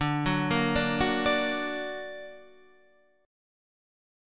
D+ Chord
Listen to D+ strummed